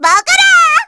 Luna-Vox_Halloween_Skill1_kr.wav